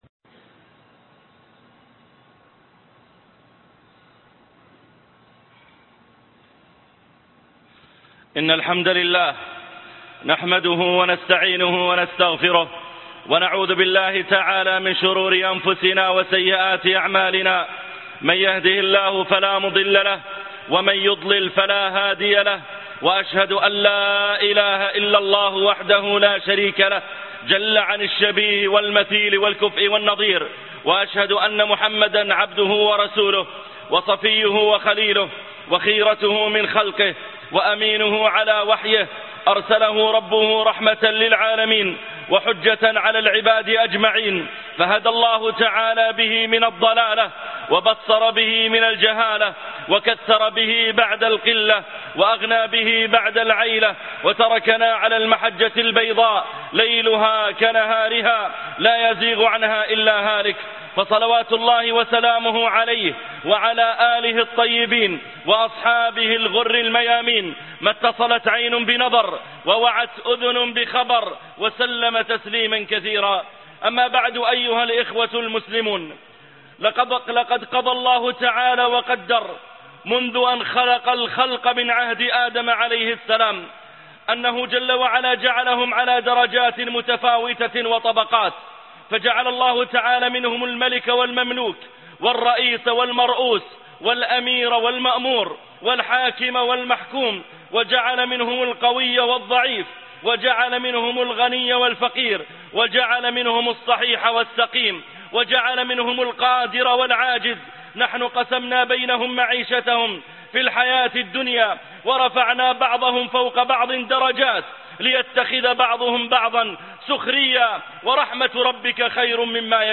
قذافى ليبيا ونهاية الظالمين - خطب الجمعة - الشيخ محمد العريفي